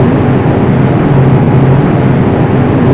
MITSUBISHI KI.57 TOPSY avec un moteur Mitsubishi Ha-102
Moteur de 14 cylindres sur deux rangées double étoile sur 2 lignes radiales à valve sur hampe avec clapet en manchon refroidi par air entrainant une hélice de 2,95 m